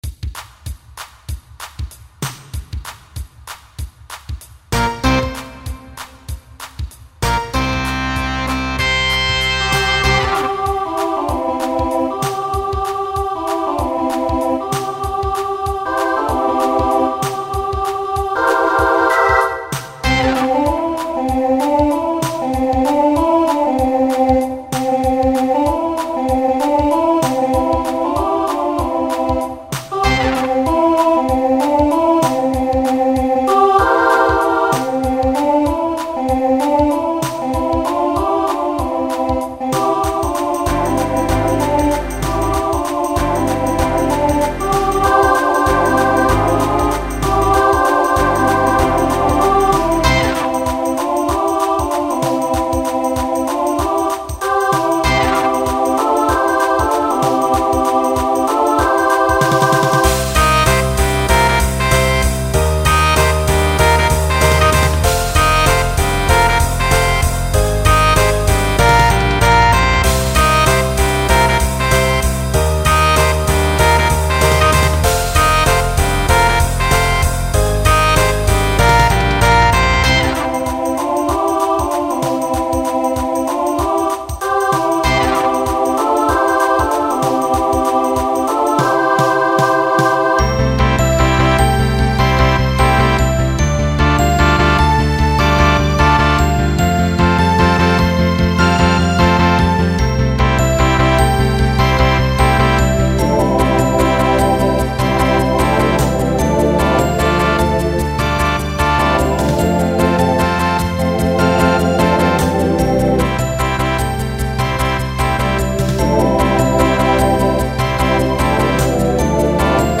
Genre Pop/Dance
Transition Voicing Mixed